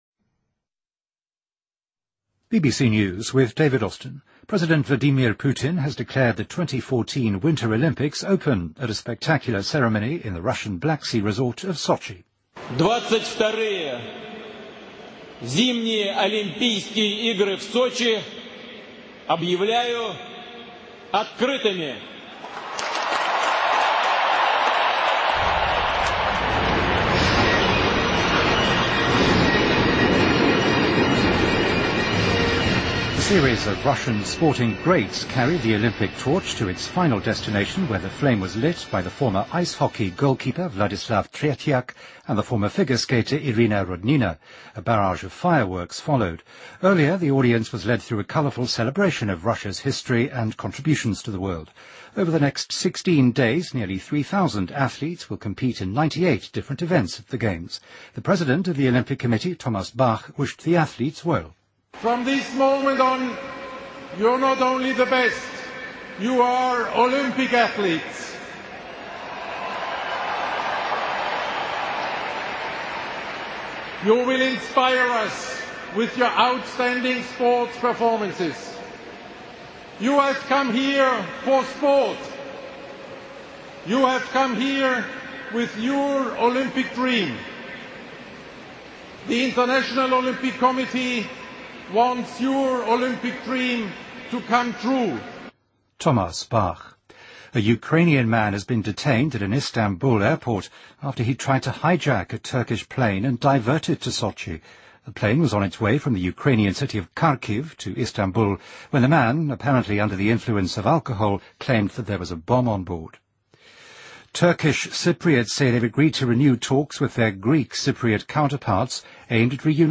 BBC news,2014-02-08